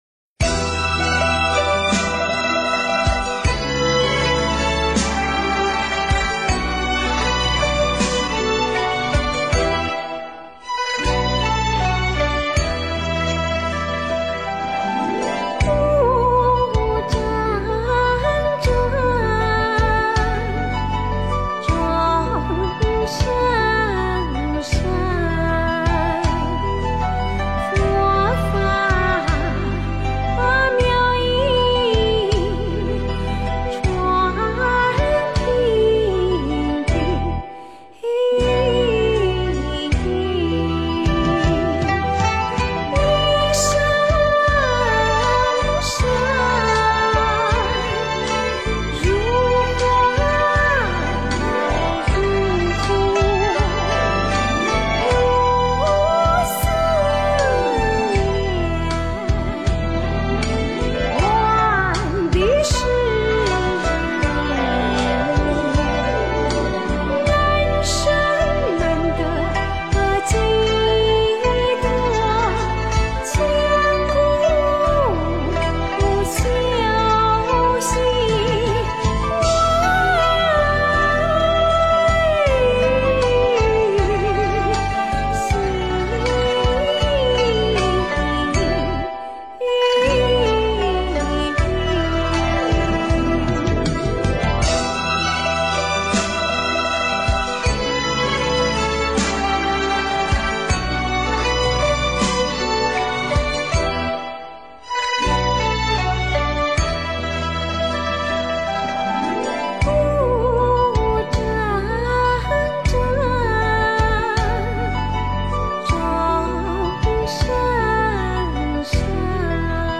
妙音 诵经 妙音--佛教音乐 点我： 标签: 佛音 诵经 佛教音乐 返回列表 上一篇： 轮回 下一篇： 生死不离 相关文章 愿成为你的善知识(国语演唱)--泰国法身寺 愿成为你的善知识(国语演唱)--泰国法身寺...